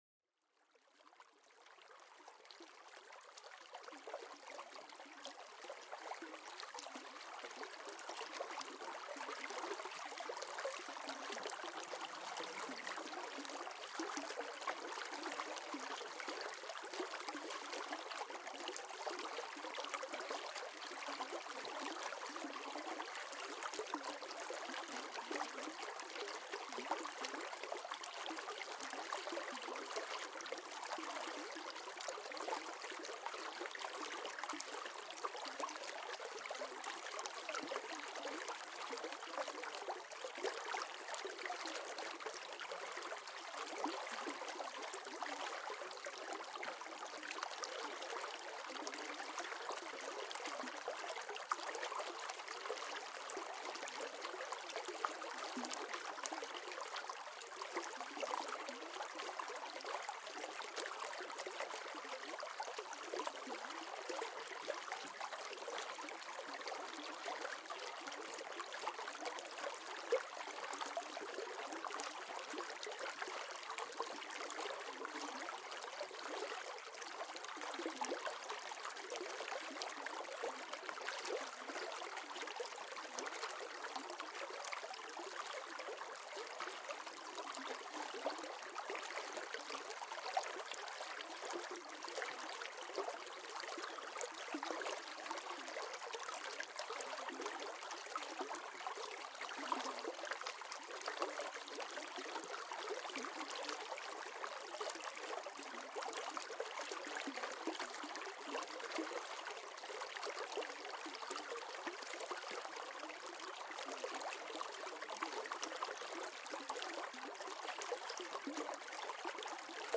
Apaisement au Fil de l’Eau : Son d’une Rivière pour un Sommeil Profond
Laissez-vous bercer par le doux murmure de l’eau qui coule dans une rivière paisible. Ce son naturel apaise l’esprit, relâche les tensions et invite à la détente absolue.